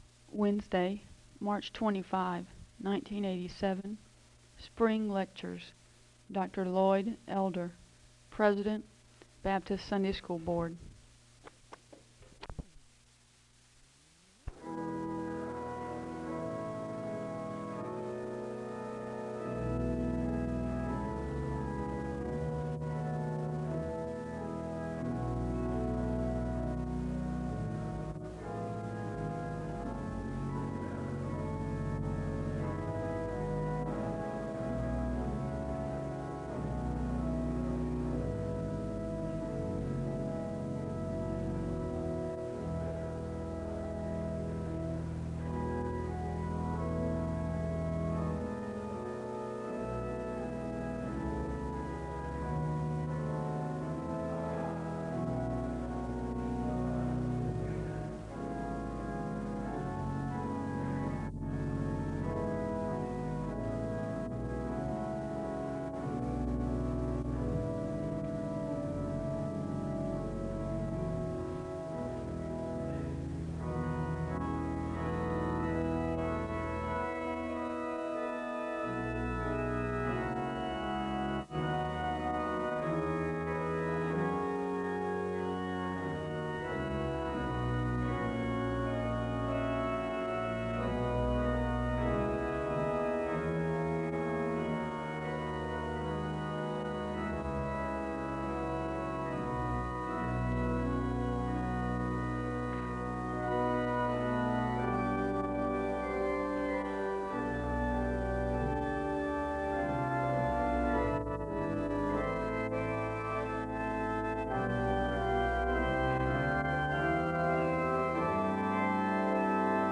The service begins with organ music (0:00-4:12). There is a moment of prayer (4:13-5:11).
Location Wake Forest (N.C.)
SEBTS Chapel and Special Event Recordings SEBTS Chapel and Special Event Recordings